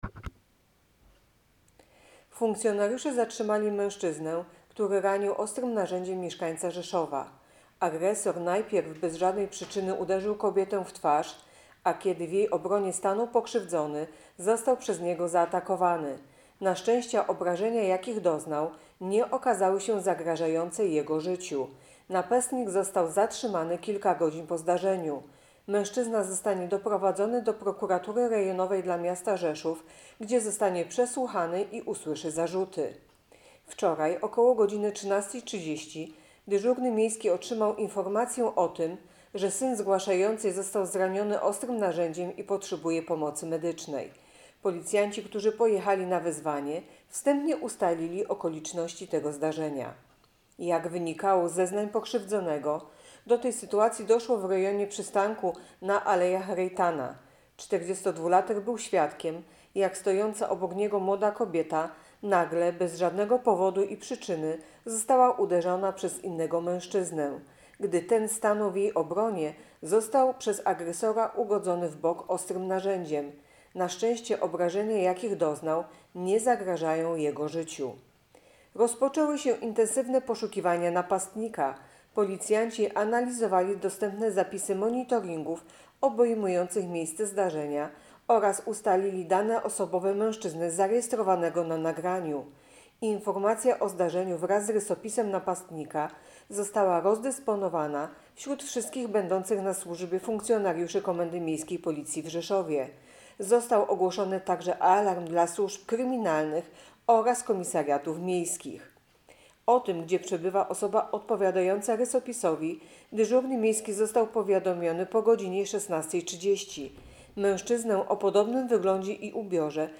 Opis nagrania: Nagranie informacji pt. Policjanci zatrzymali 65-latka, który zaatakował mieszkańca Rzeszowa.